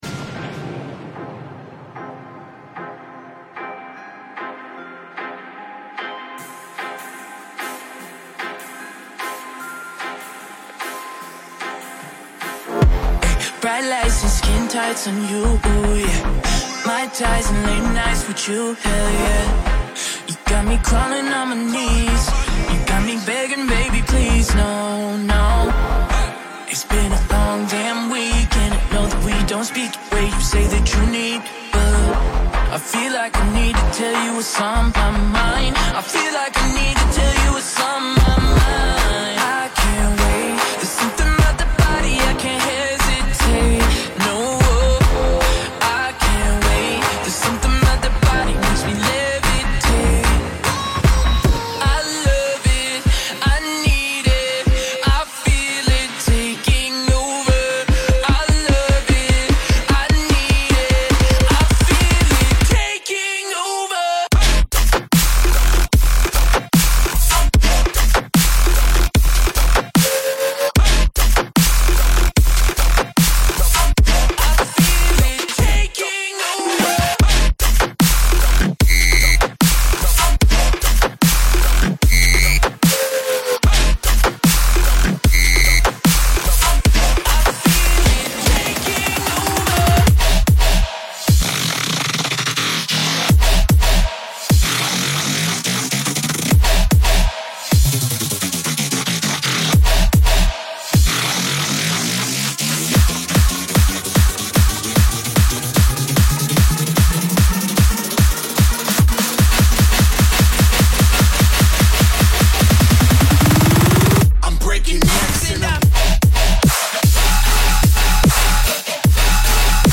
Liveset
Genre: Dubstep